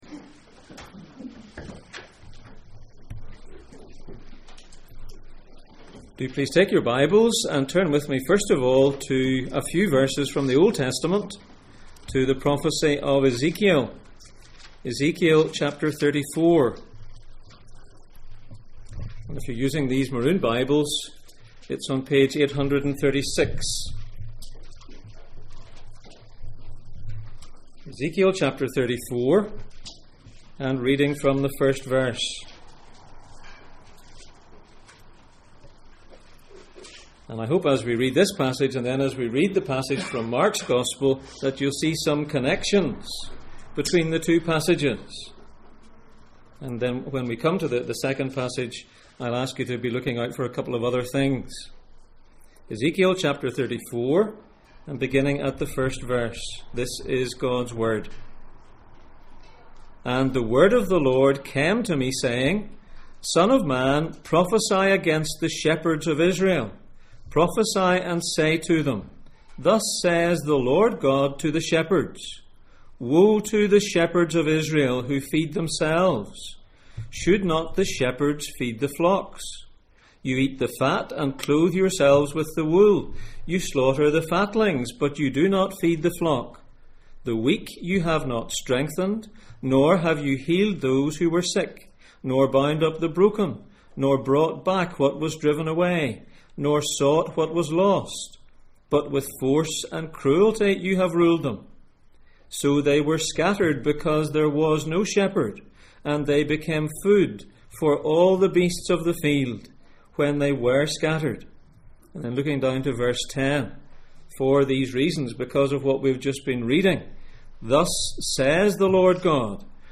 Jesus in Mark Passage: Mark 6:30-44, Ezekiel 34:1-16, Numbers 27:16-18 Service Type: Sunday Morning